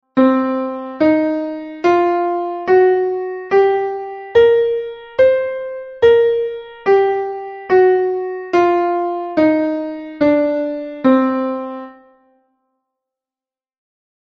- The blues scale: this style was developed in North America with clear influences from African music. The scale has 8 sounds.
escalablues.mp3